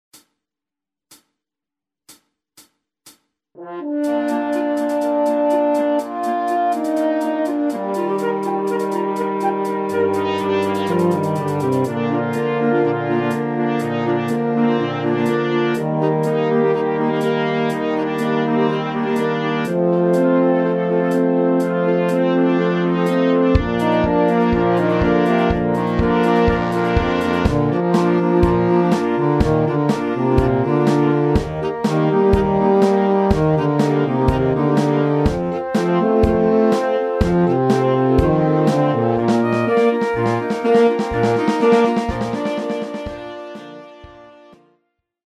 niemiecki szlagier